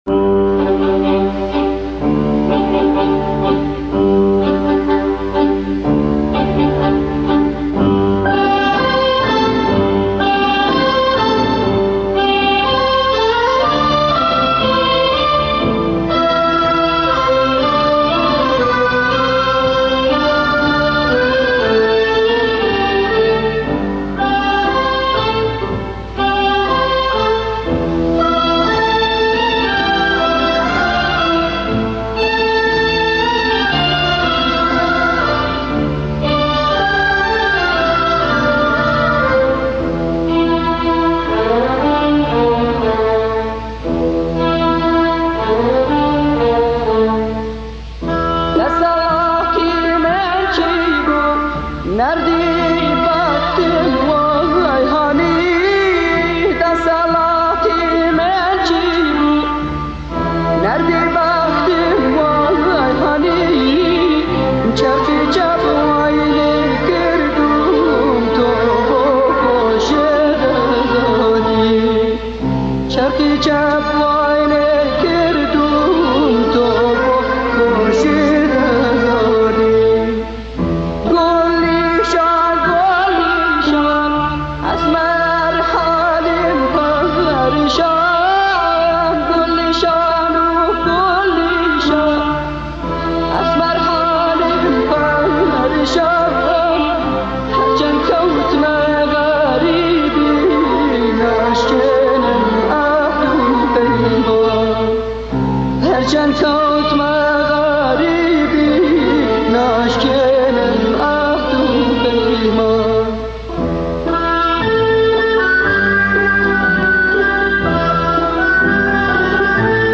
فۆلکلۆر